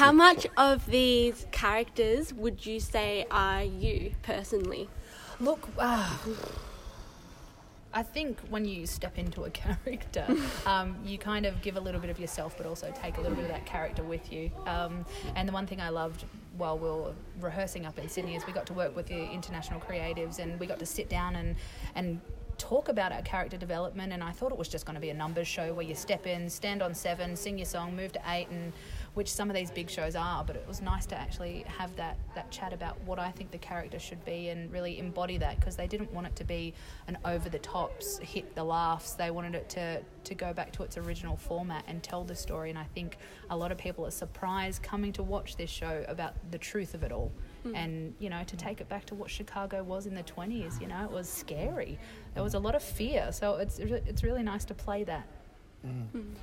Chicago Media Call